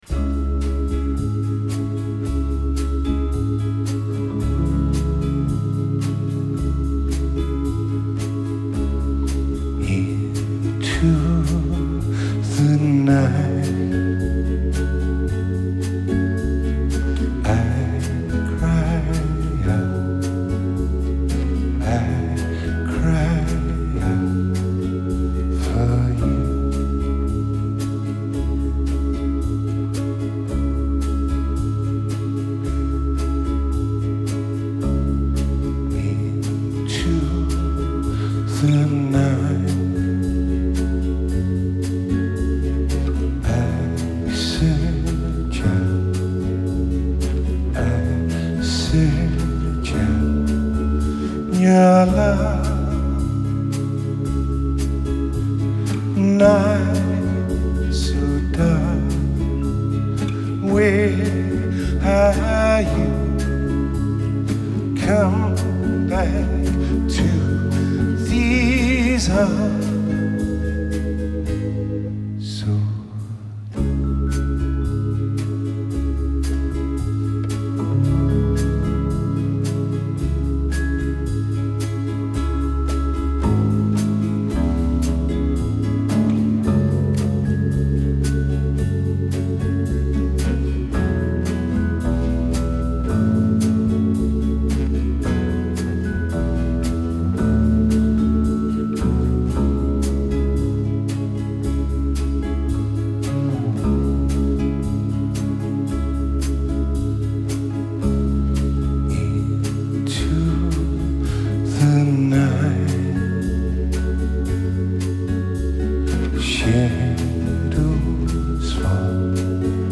Genre: Alternative, Indie Rock, Chamber Pop